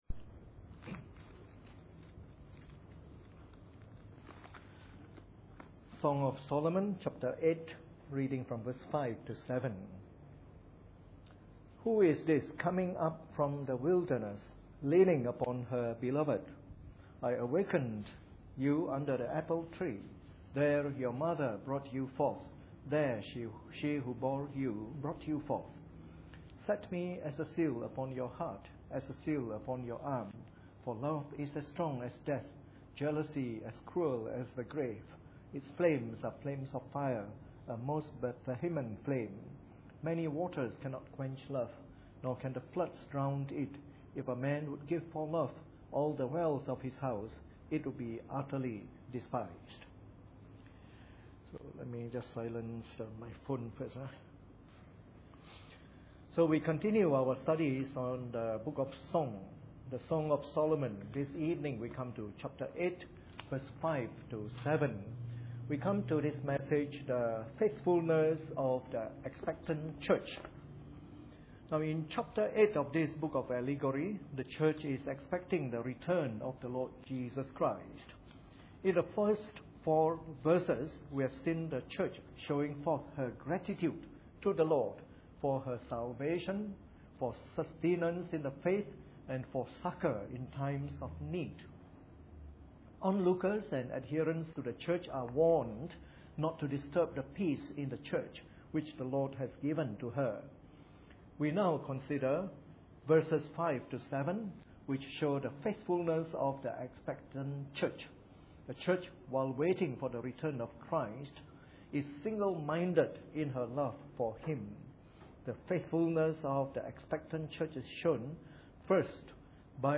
Preached on the 22nd of February 2012 during the Bible Study from our series on the Song of Solomon.